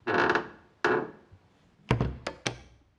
SFX_Door_Close_03.wav